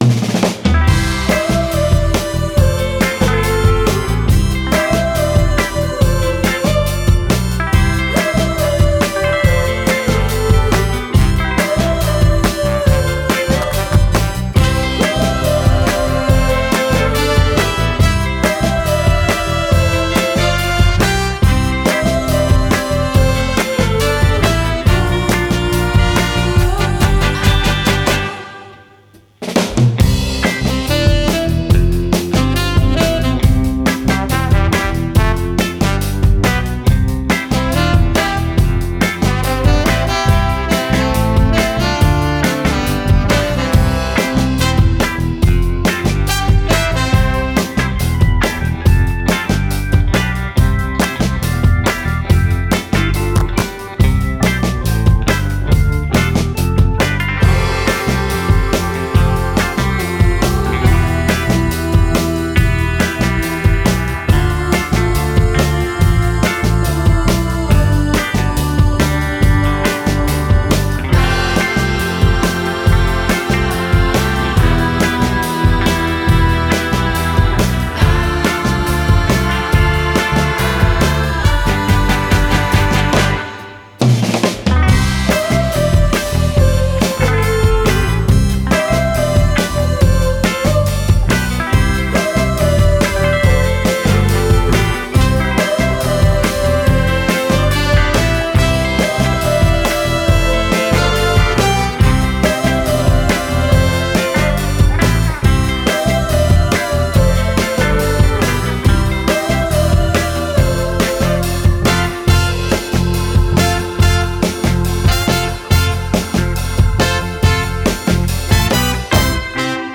Жанр: Jazz | Pop | Fusion